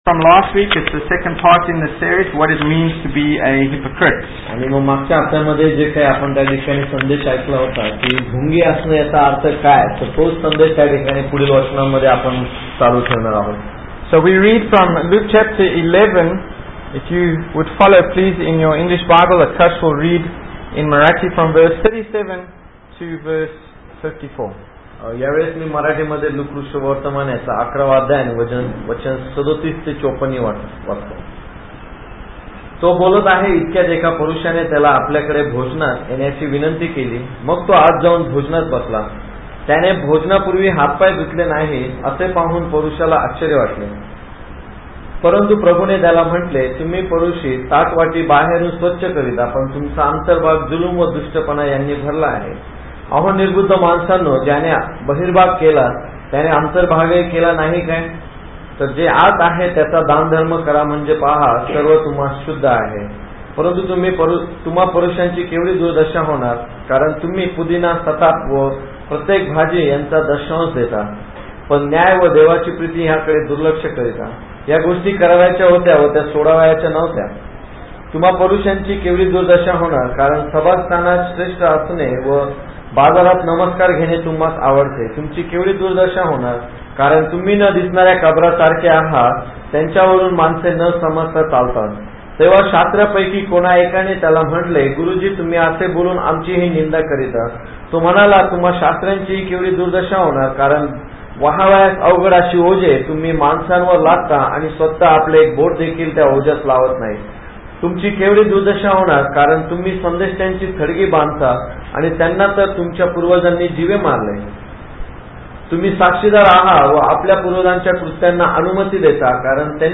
Bible Text: Luke 11:45-57 | Preacher